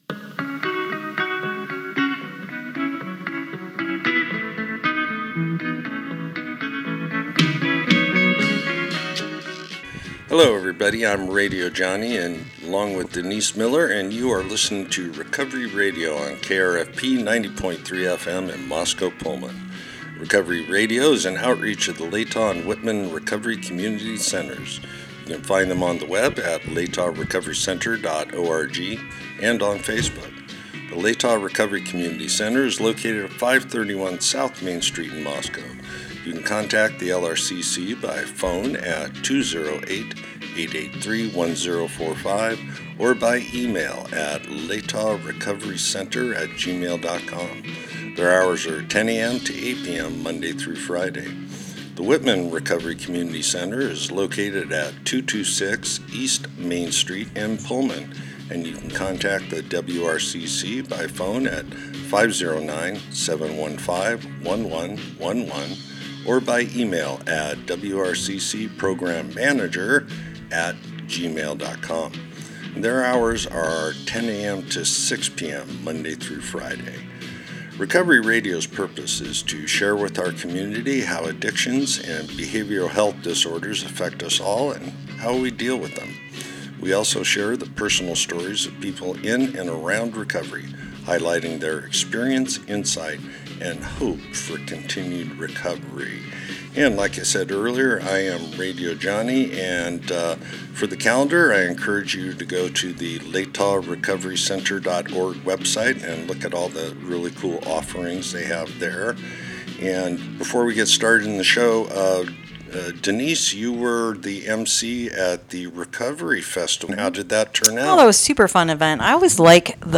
Type: Interview